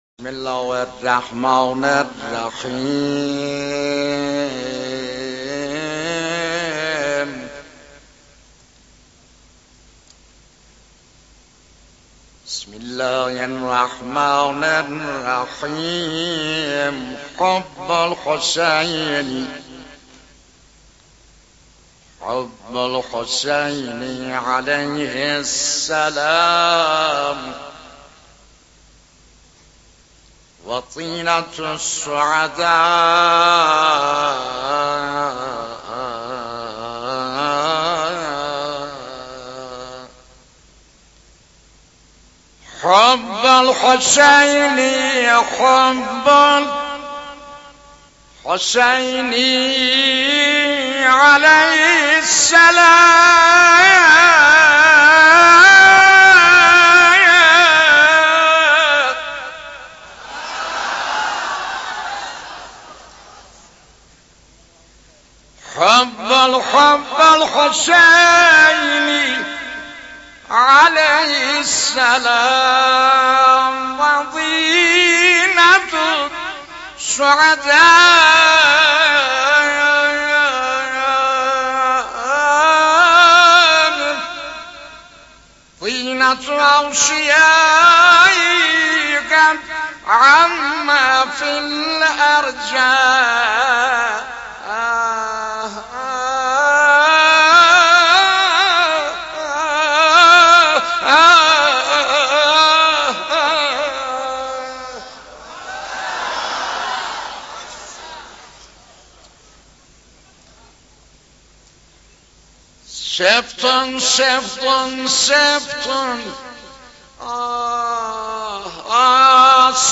مولودی آذری مولودی ترکی